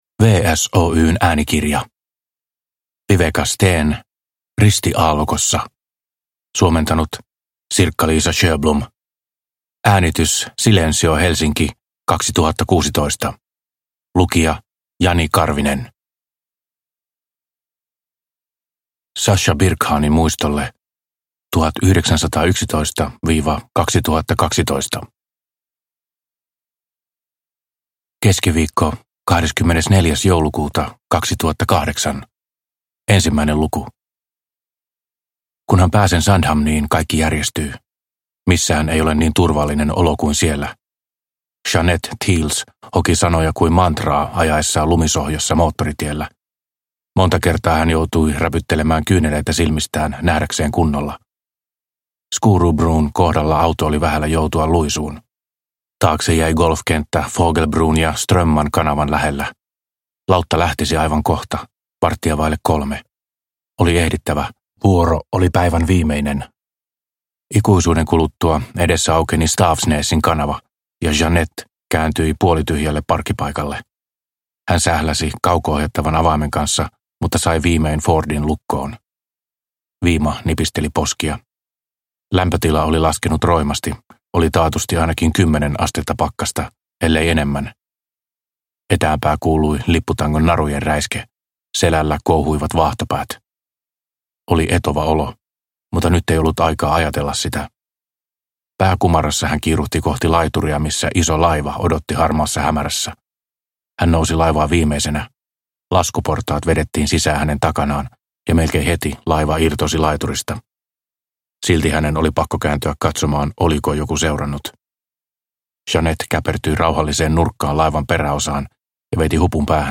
Ristiaallokossa – Ljudbok – Laddas ner